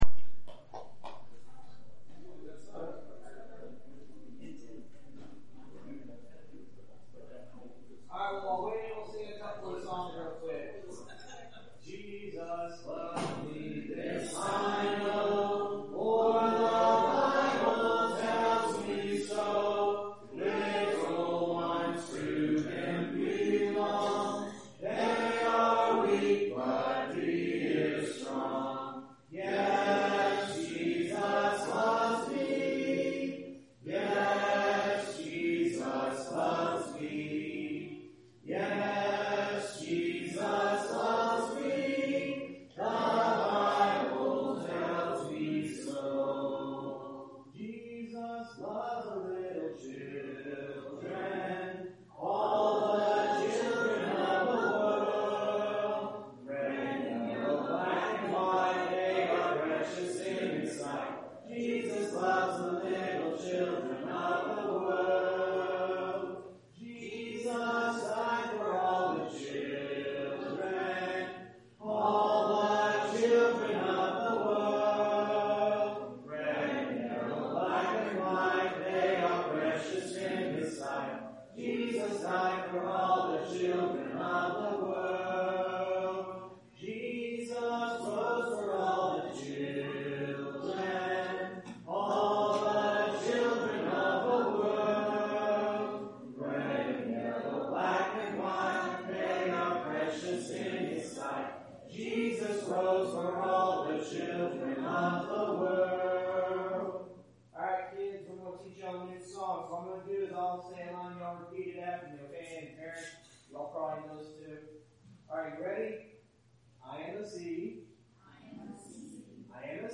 August 19th – Sermons